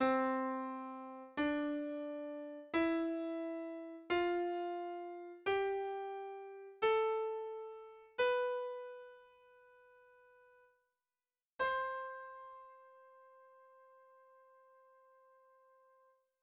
Escala-.wav